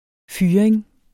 Udtale [ ˈfyːɐ̯eŋ ]